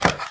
STEPS Wood, Reverb, Walk 20.wav